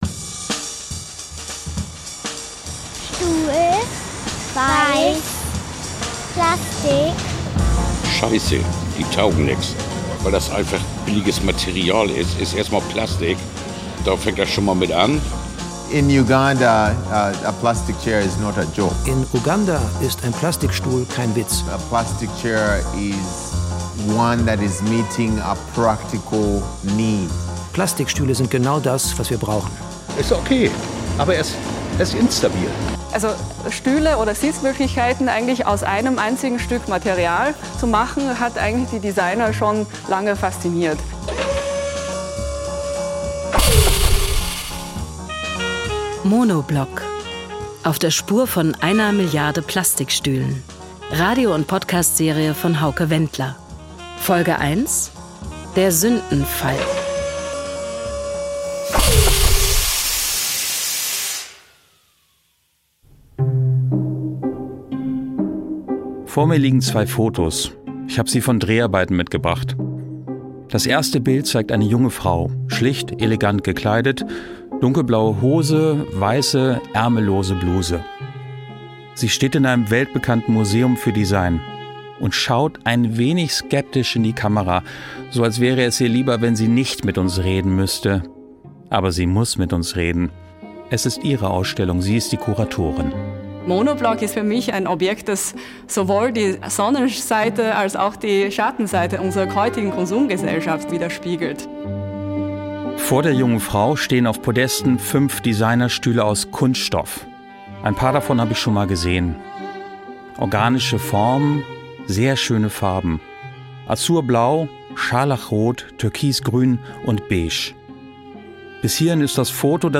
… continue reading 7 एपिसोडस # Gesellschaft # Dokumentationen # NDR Info